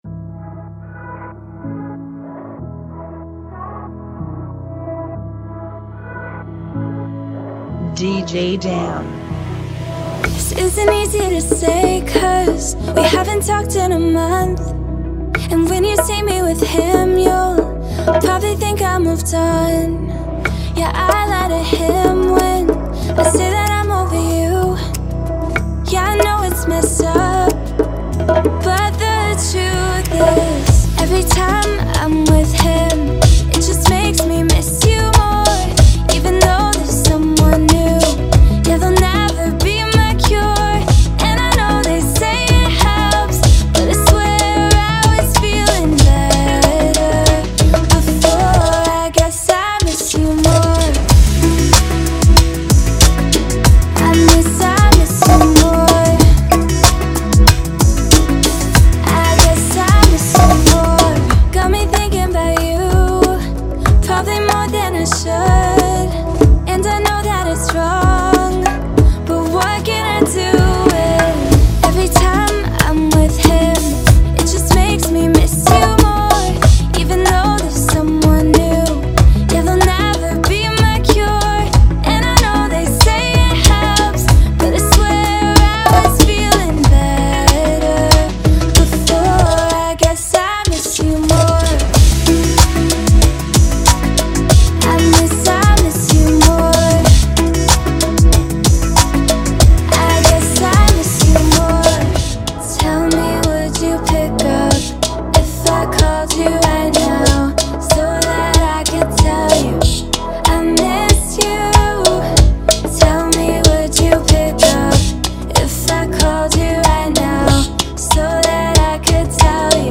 94 BPM
Genre: Bachata Remix